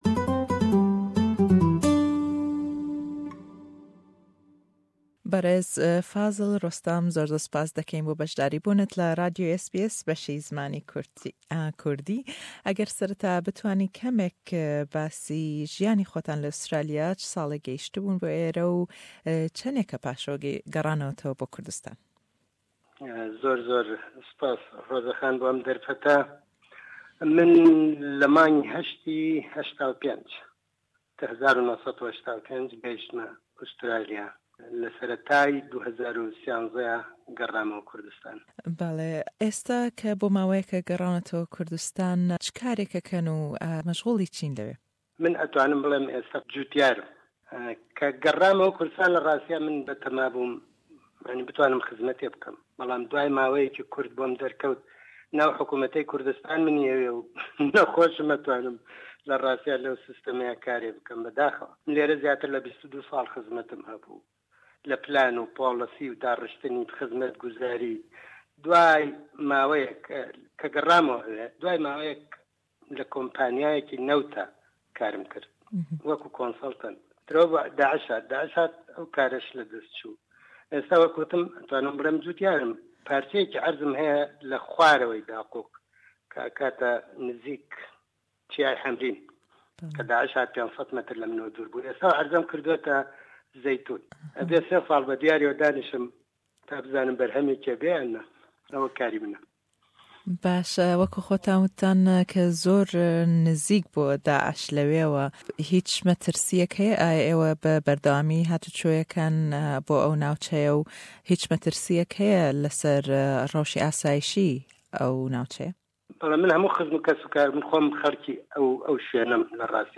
Le em hevpeyvîne da ême sebaret be ew gorran-karîyaney le Kurdistan rûyandawe pissîyarî lê deke, rûwangey ew bo ayindey Kurdistan....